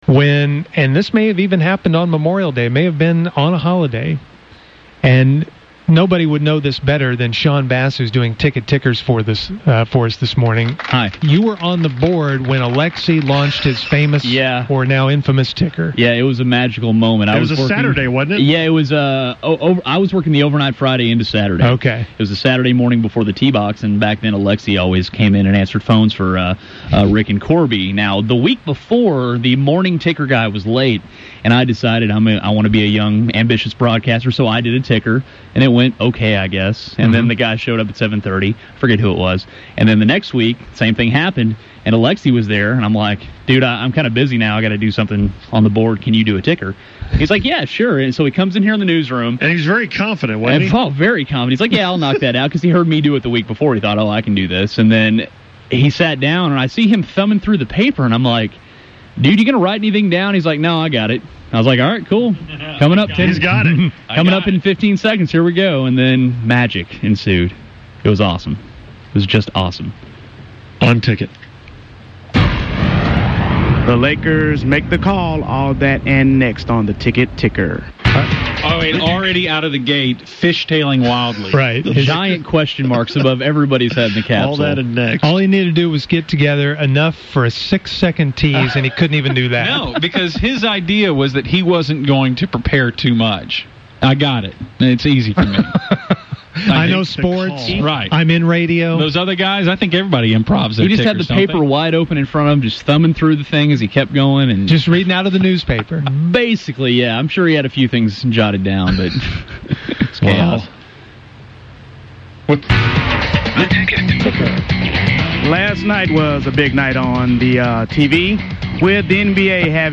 The Musers pulled out some old audio while no one was listening Memorial Day.
unfortunate and uncomfortable sportscast from Ball State University